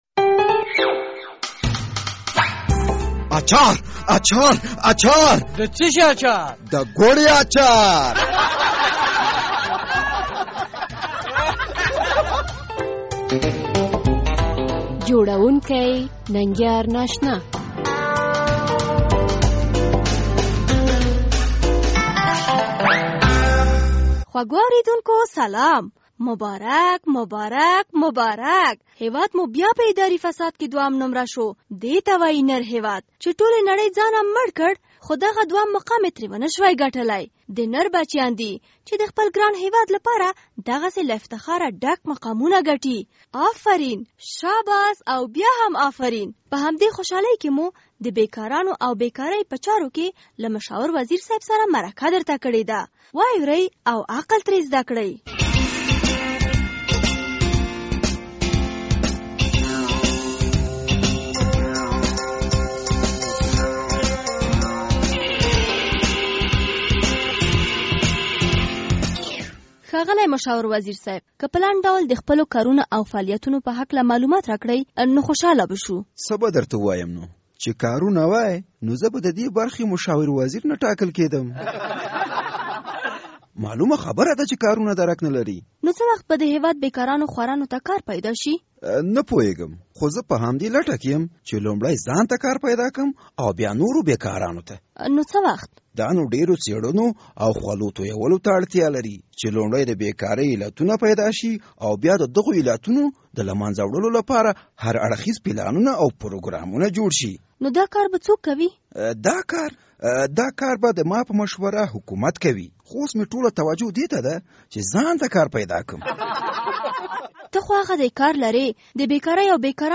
د ګوړې اچارپه دې خپرونه کې لومړی د بې کارۍ او بې کارانو په چارو کې د مشاور وزیر صیب مرکه..